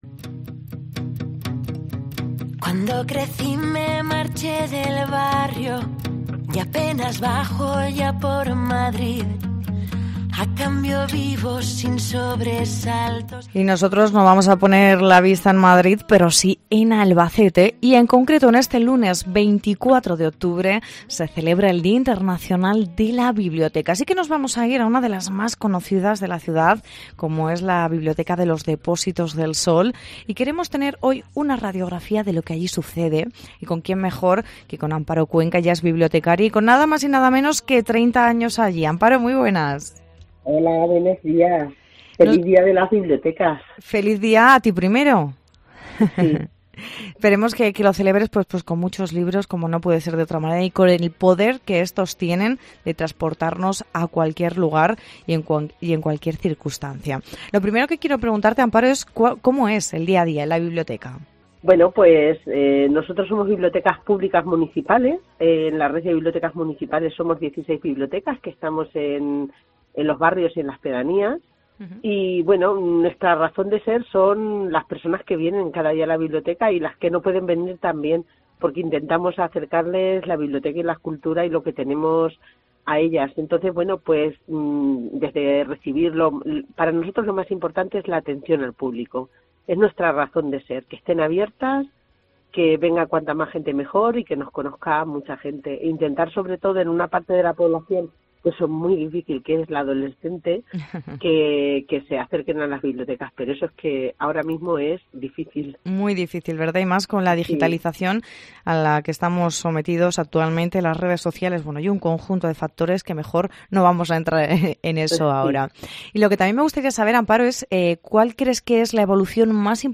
Nos vamos a una de las más conocidas de la ciudad , la biblioteca municipal de los depósitos del Sol